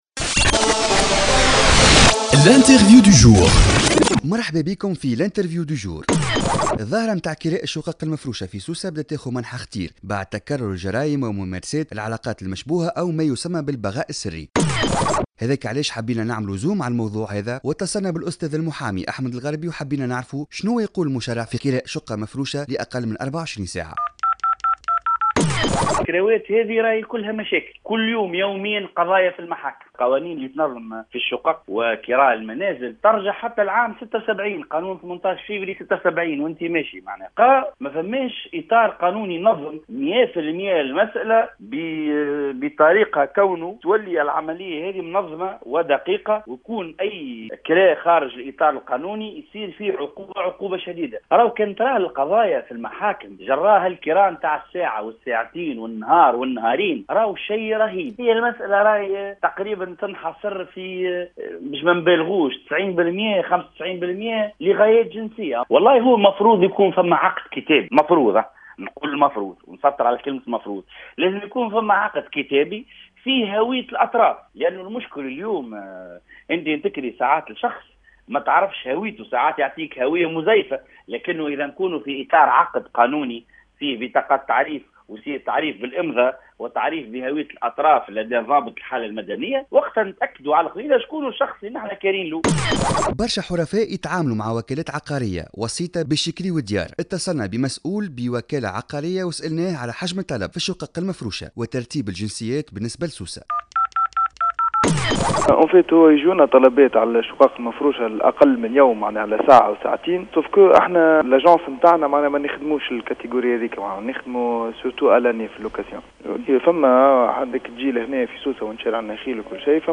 تحقيق